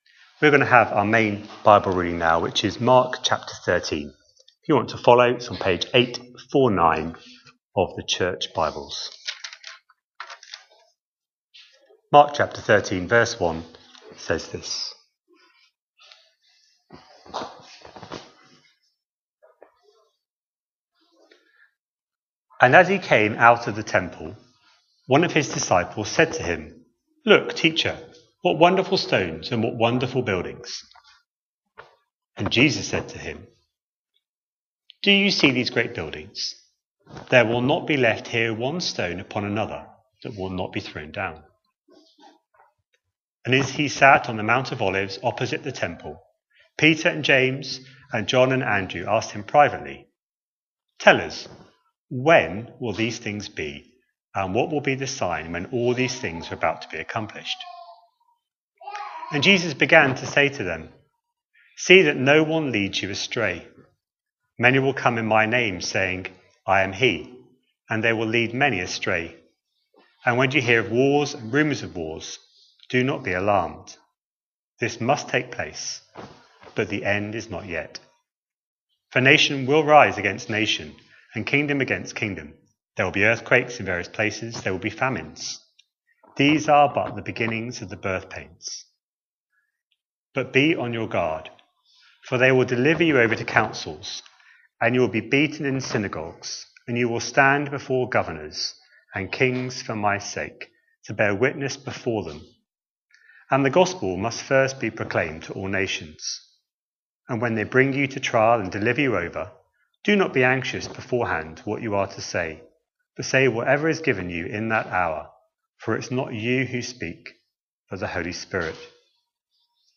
A sermon preached on 8th February, 2026, as part of our Mark 25/26 series.